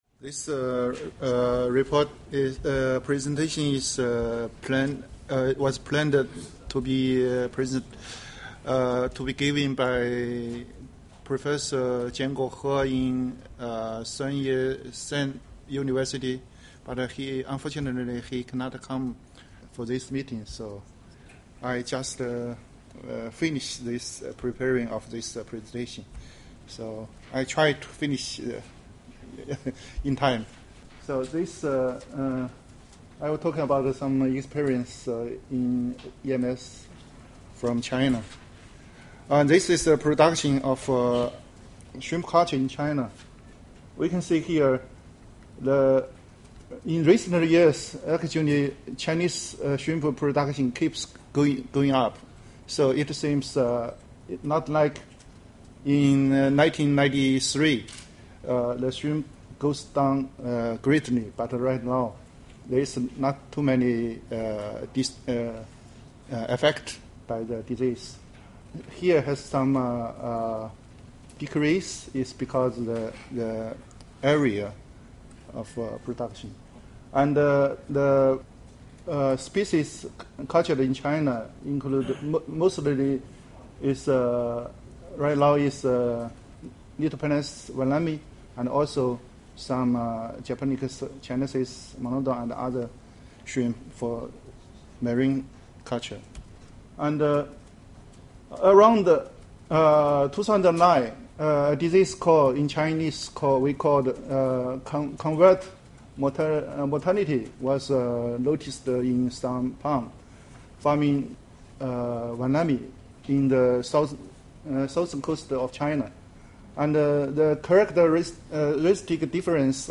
Presentation on the impact of acute hepatopancreatic necrosis syndrome on shrimp farming in China.